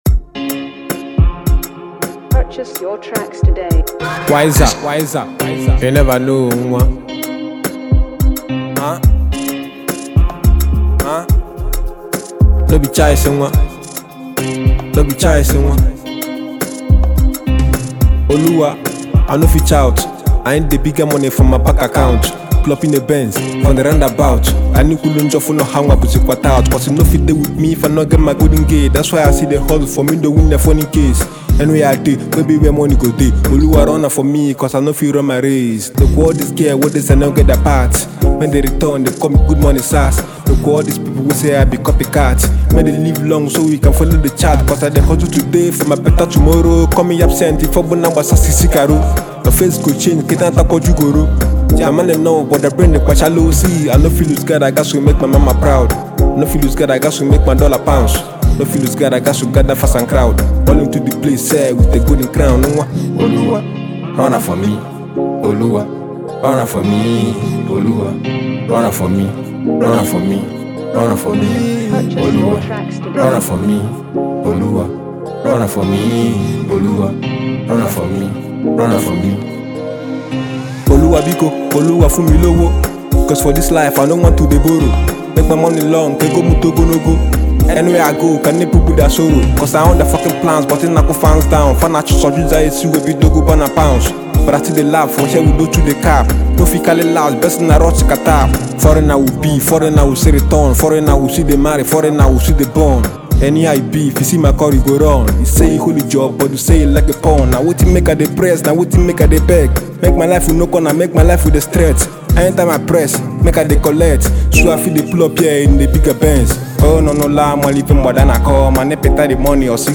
heart- rendering hit track